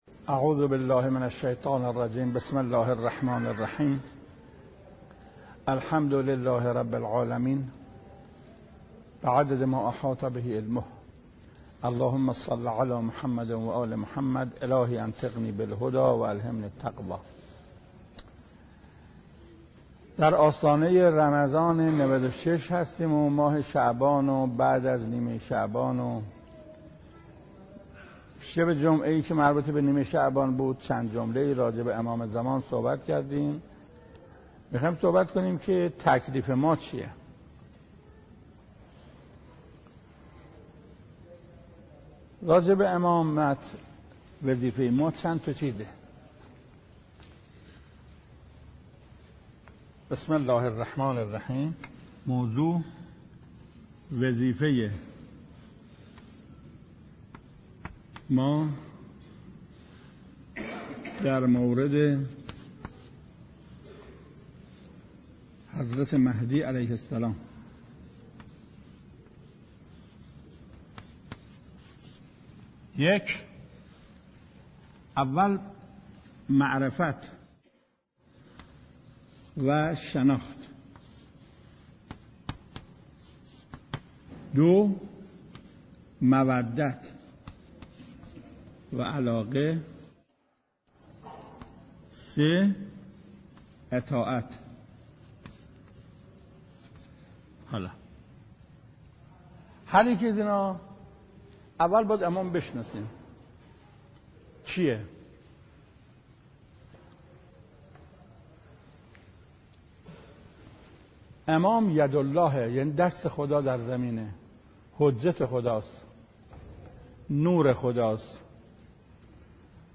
قاری : حجت الاسلام شیخ محسن قرائتی